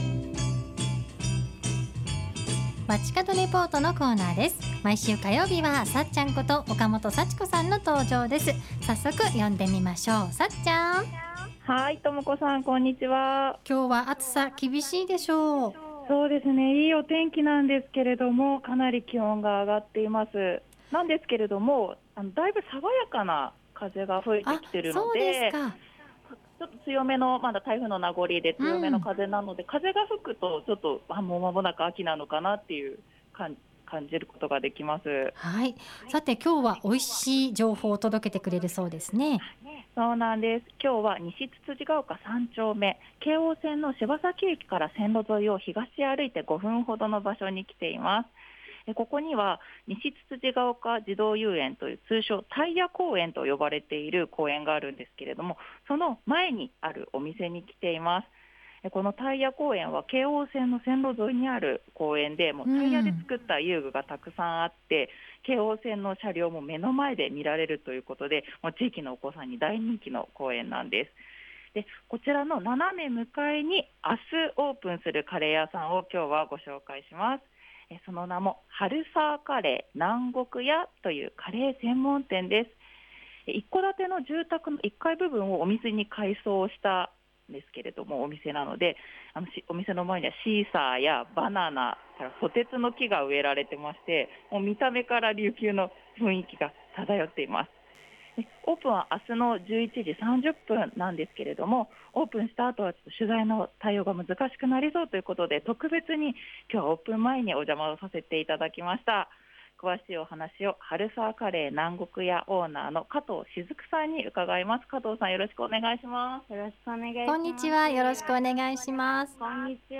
午後のカフェテラス 街角レポート
中継は西つつじヶ丘3丁目から！
今日は特別にオープン前のお店にお邪魔させていただくことができました。